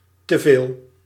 Ääntäminen
IPA : /əkˈsɛs/ IPA : /ˈɛksɛs/